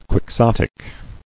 [quix·ot·ic]